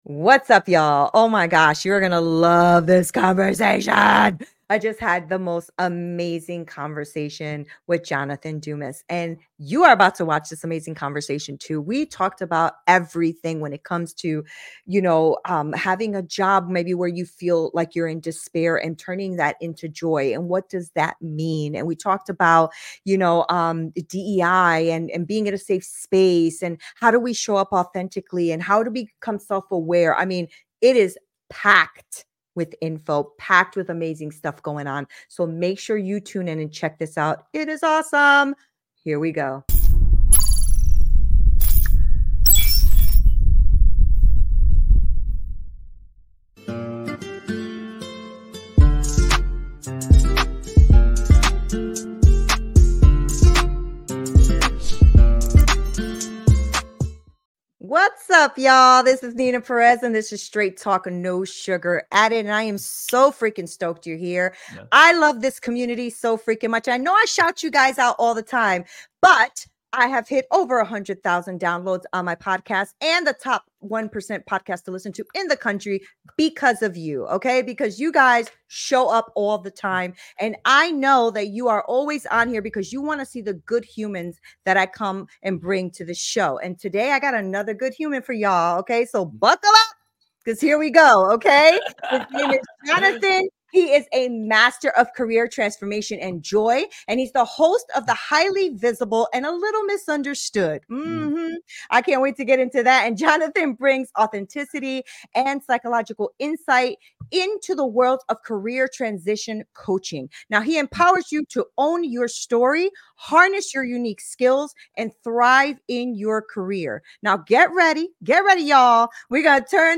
🚀 Dive into a soul-stirring conversation about transforming your work life from mundane to magnificent, using authenticity and deep psychological insights. 🌈 We explore what it means to truly harness your unique skills and thrive—no matter where you are in your career journey.